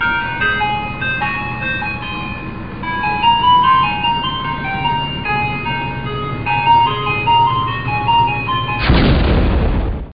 描述：在洛杉矶市中心的一辆冰淇淋车，在循环播放The Entertainer音乐
标签： 冰淇淋车 音乐 循环 经典 场景
声道立体声